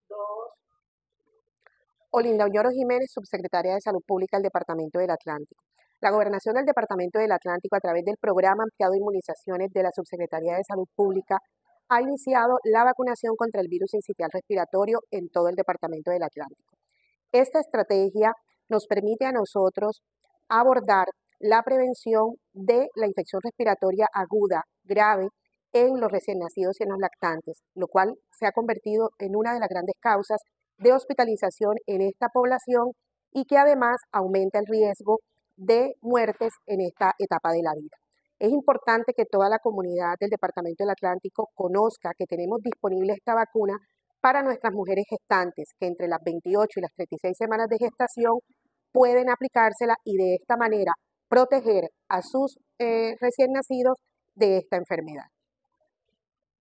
Audio-Olinda-Onoro-subsecretaria-de-Salud-Publica-Atlantico-.mp3